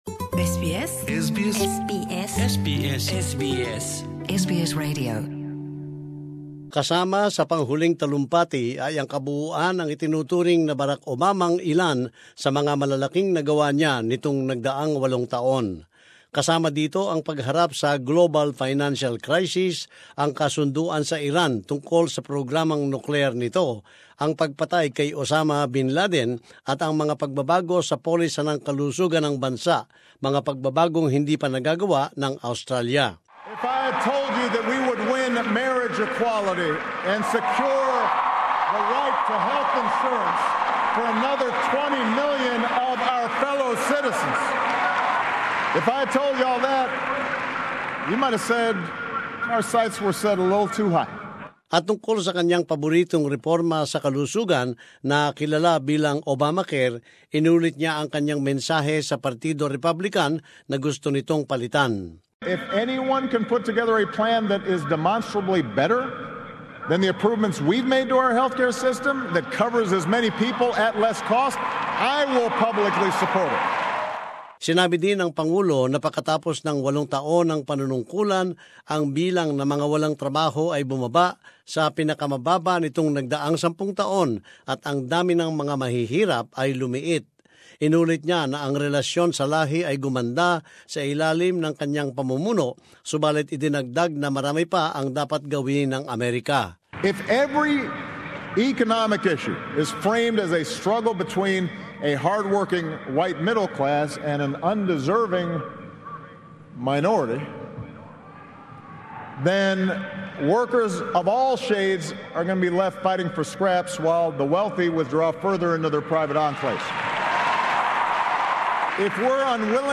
President Obama delivers farewell address